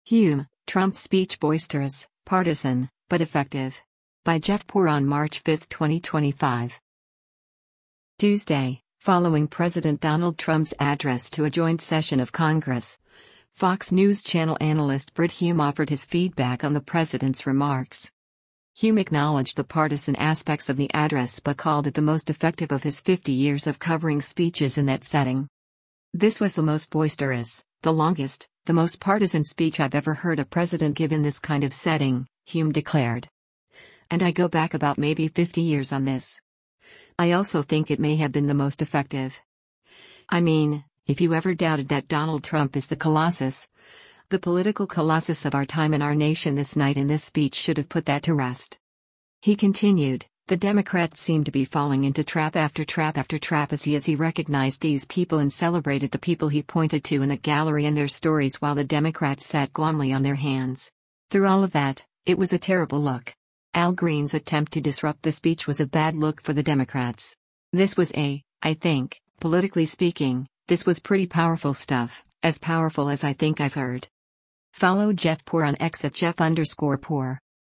Tuesday, following President Donald Trump’s address to a joint session of Congress, Fox News Channel analyst Brit Hume offered his feedback on the President’s remarks.